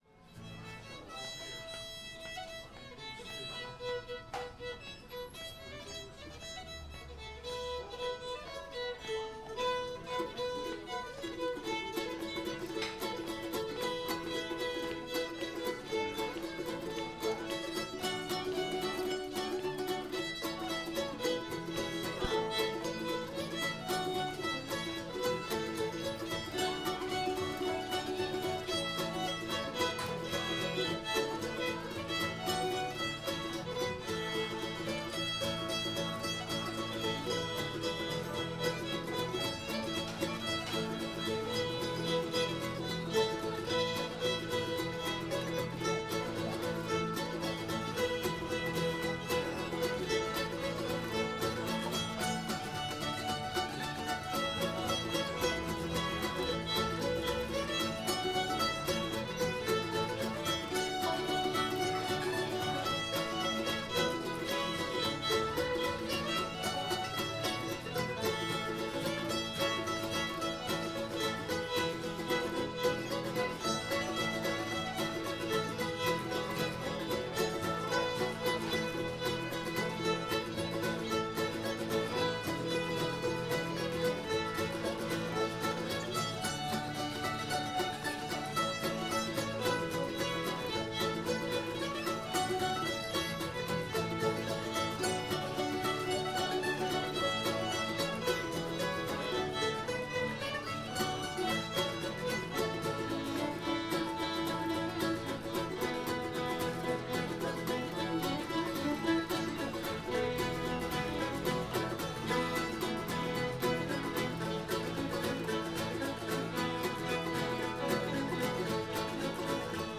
old blair store [A]